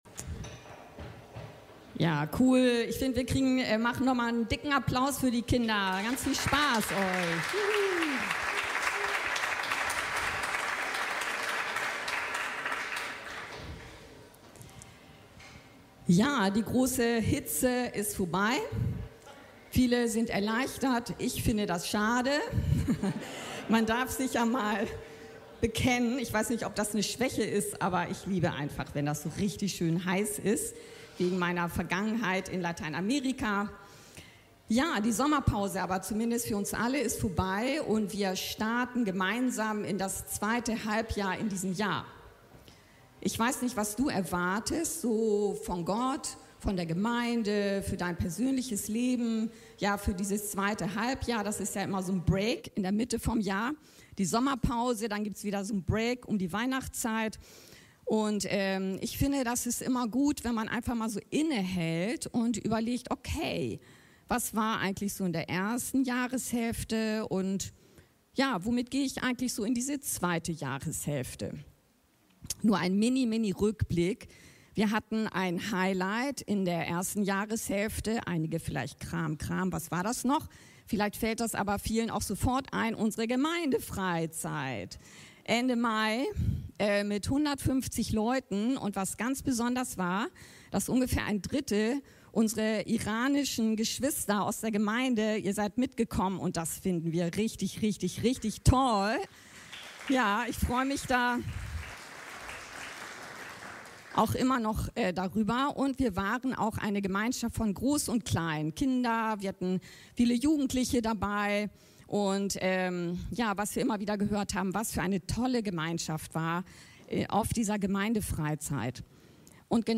Wie lerne ich, siegreich zu beten? (1. Kön. 18,41-46) ~ Anskar-Kirche Hamburg- Predigten Podcast